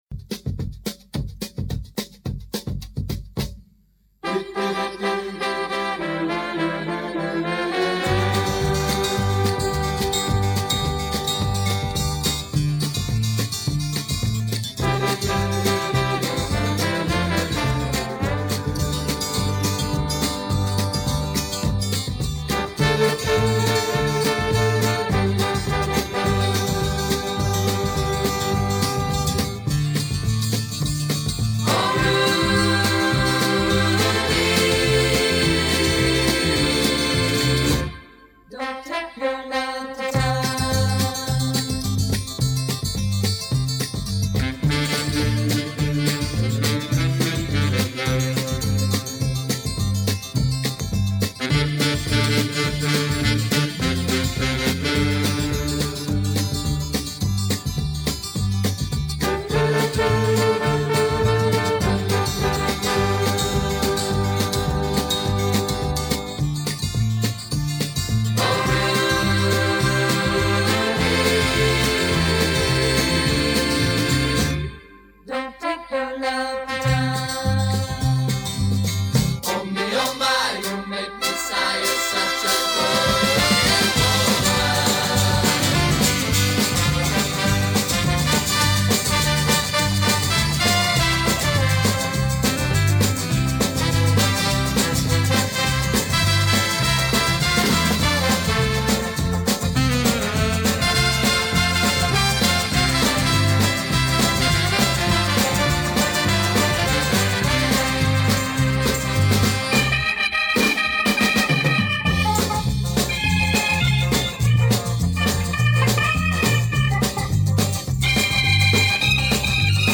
Вот оцифровка немецкой фирмы.